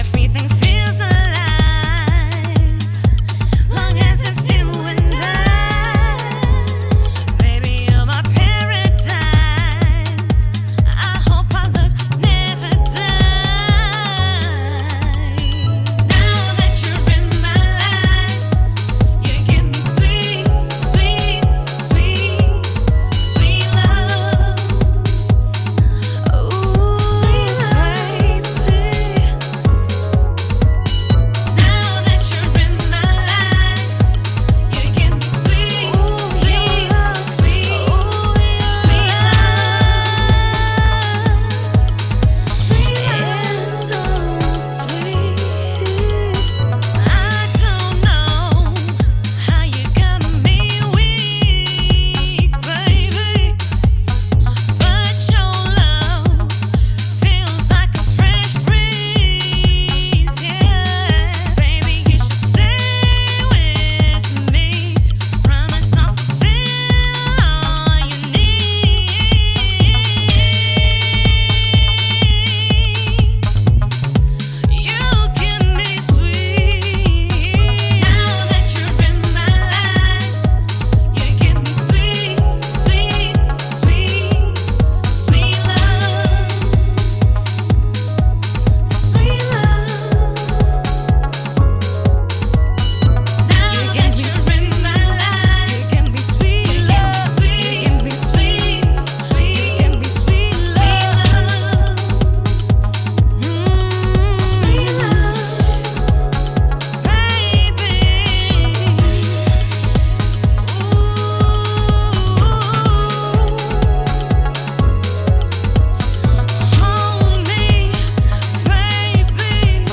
Both are 2 incredibly deep messages to HOUSE MUSIC.